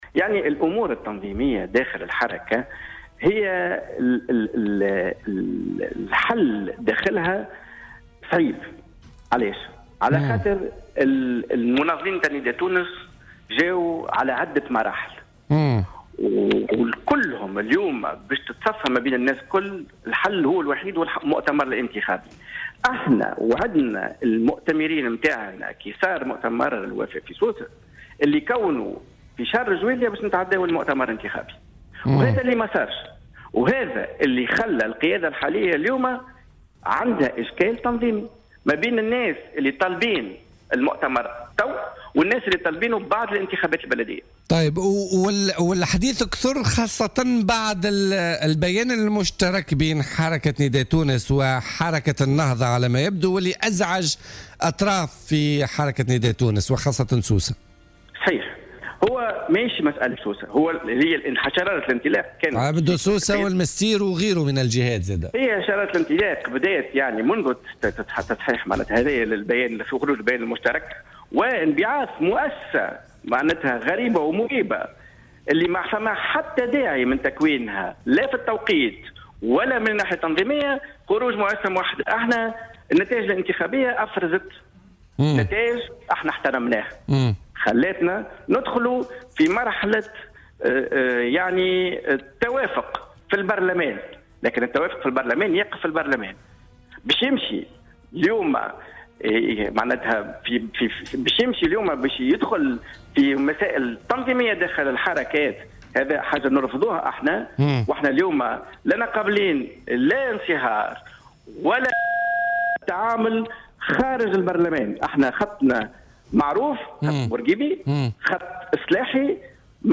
وأضاف في مداخلة له اليوم في برنامج "بوليتيكا" أن القيادة الحالية لديها إشكال تنظيمي وأن الحل الوحيد هو المؤتمر الانتخابي، وفق تعبيره.